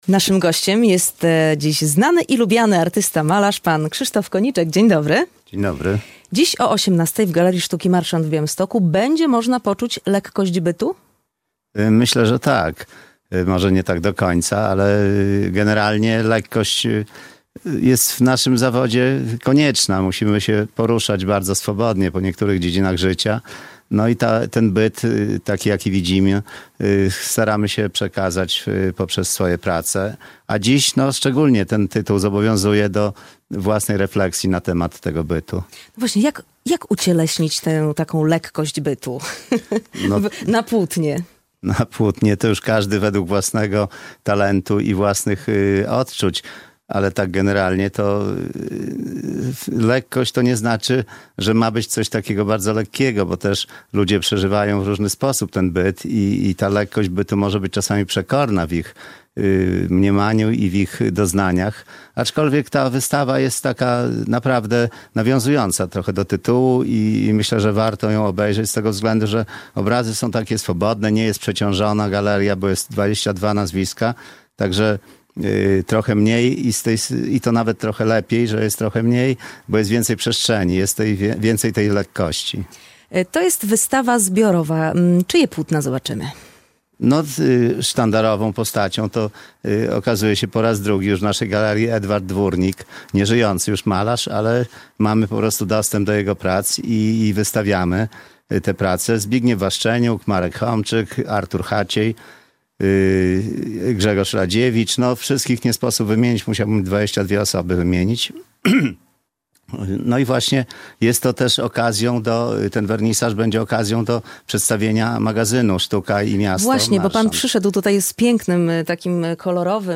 Studio Radia Bialystok